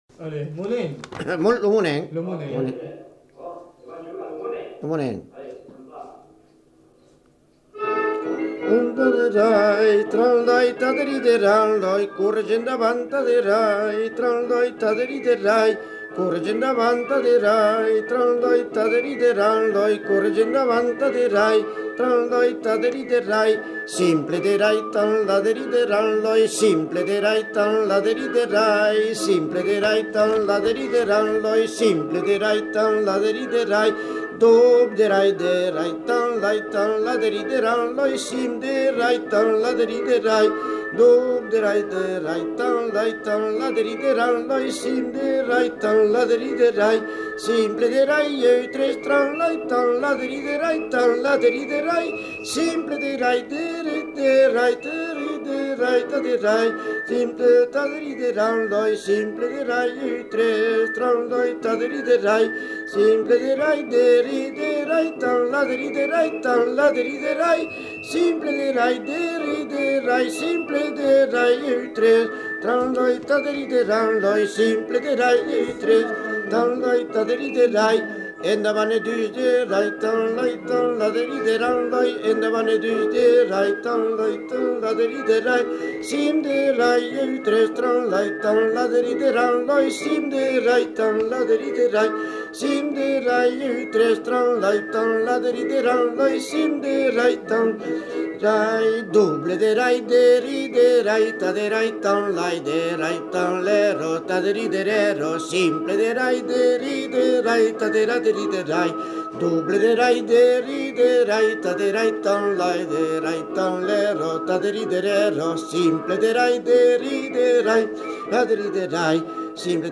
Aire culturelle : Béarn
Lieu : Bielle
Genre : chant
Effectif : 1
Type de voix : voix d'homme
Production du son : chanté
L'interprète chante les pas du saut sur un fond musical enregistré